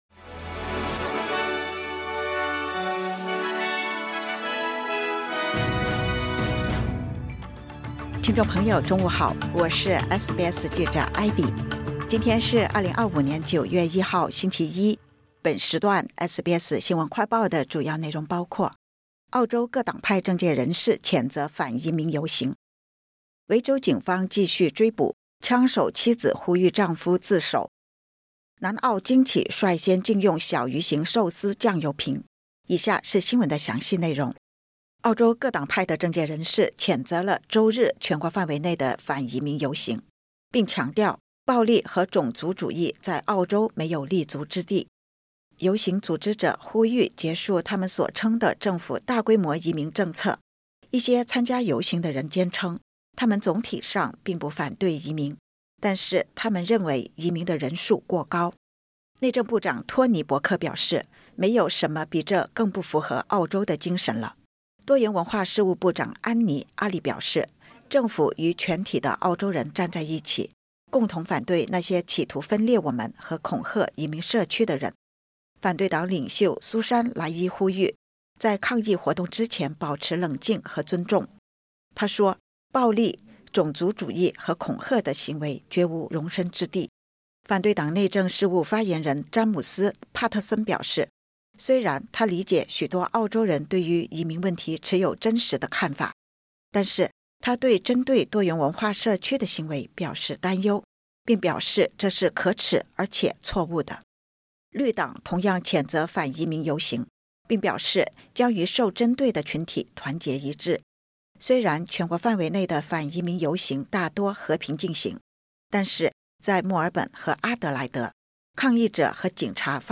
SBS 新闻快报